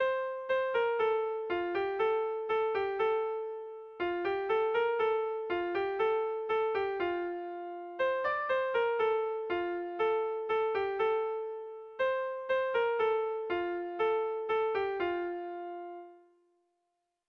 Melodías de bertsos - Ver ficha   Más información sobre esta sección
Kontakizunezkoa
ABDE